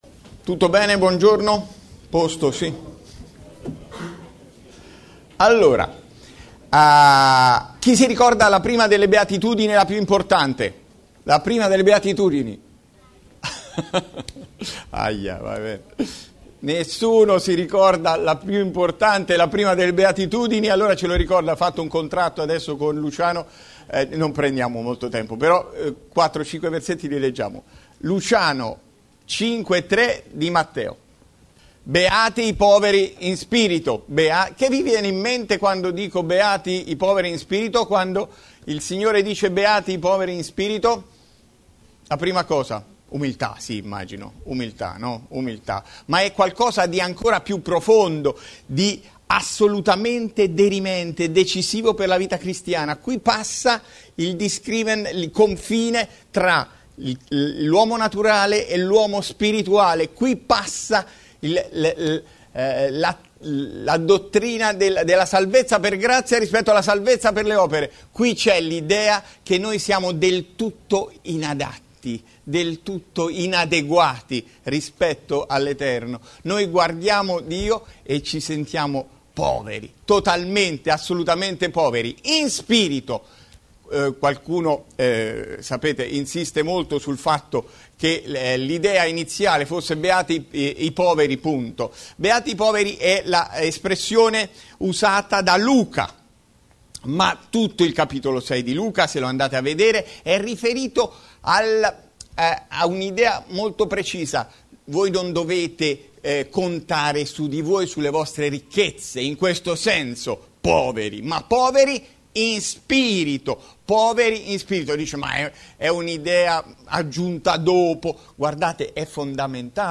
Bible Text: Matteo 5:3 | Preacher